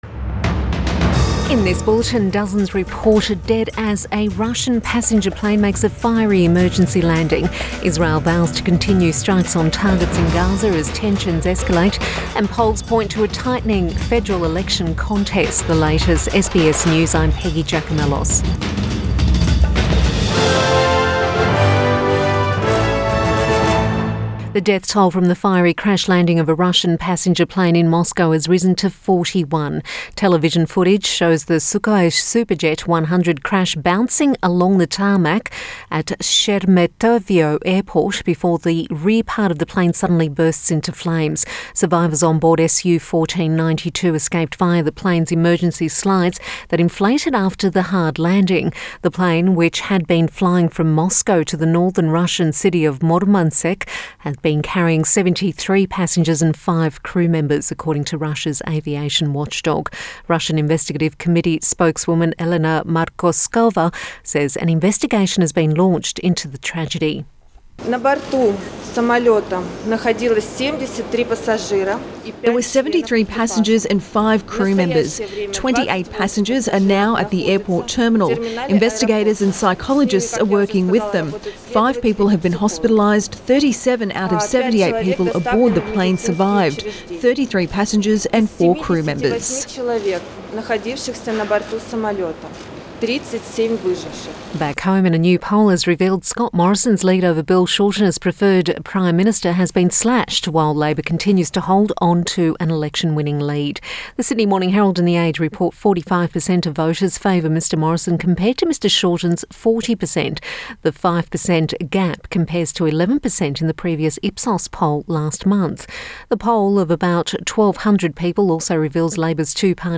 Midday bulletin 6 May